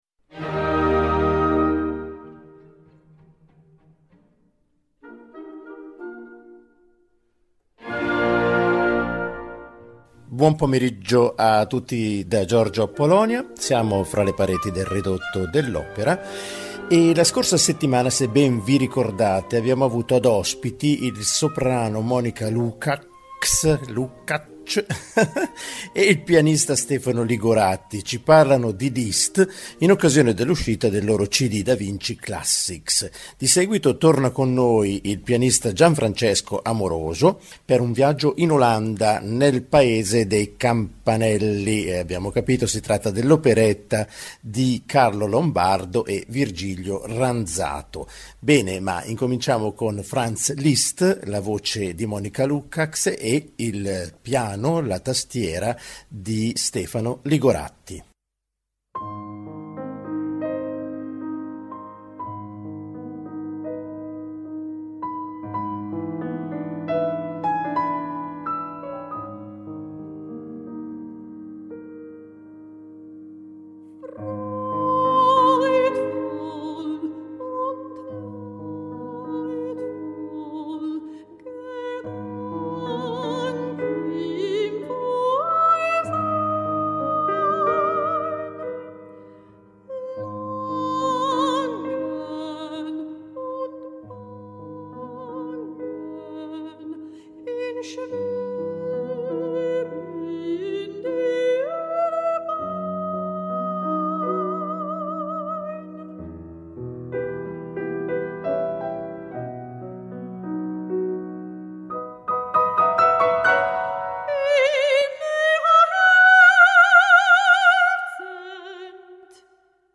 Ancora per questa settimana ospiti il soprano ungherese